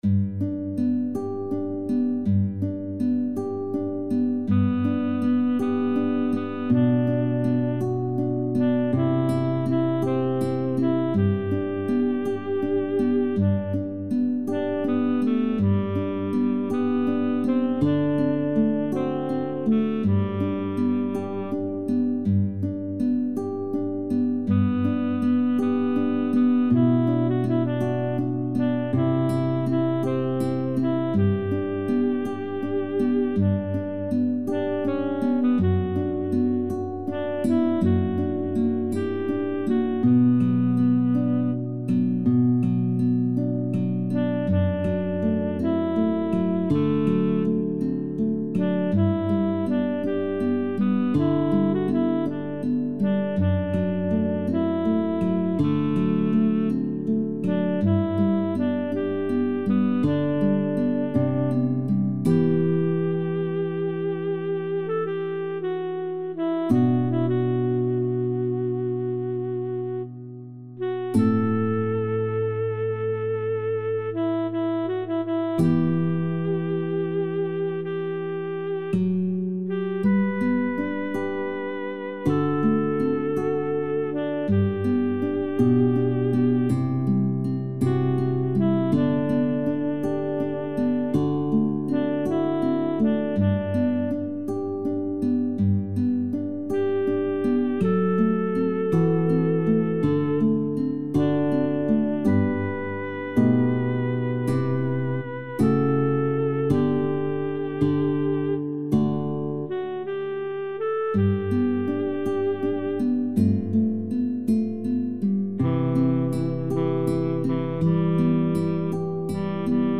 SATB met solo's
Een arrangement voor koor met alt- en baritonsolo